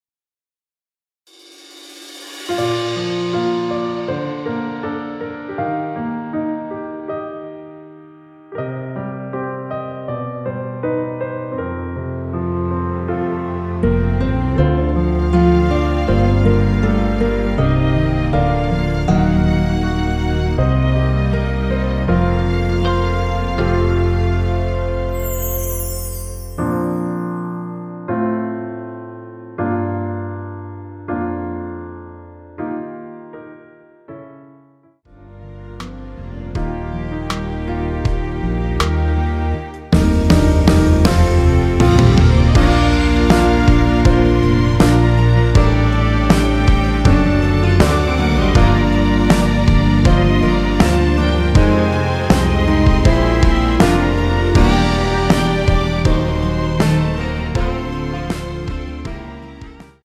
원키에서(-3)내린 MR입니다.
Bb
앞부분30초, 뒷부분30초씩 편집해서 올려 드리고 있습니다.
중간에 음이 끈어지고 다시 나오는 이유는